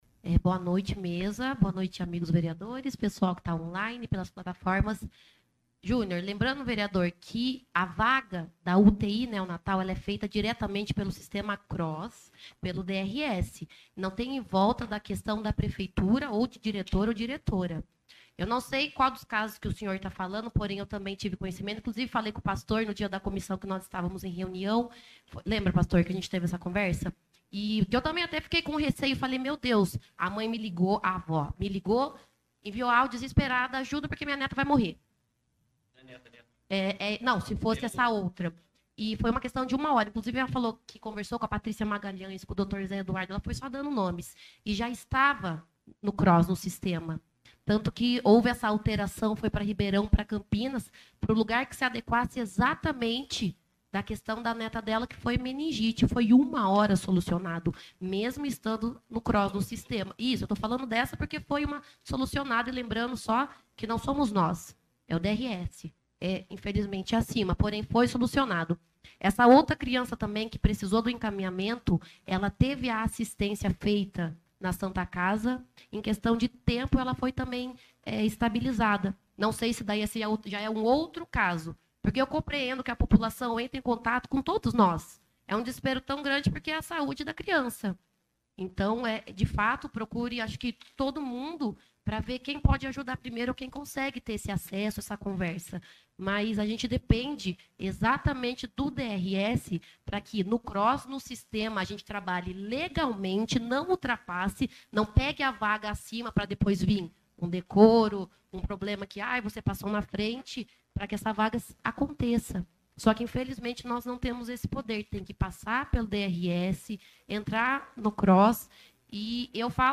Ouça o pronunciamento de Aline Luchetta na íntegra: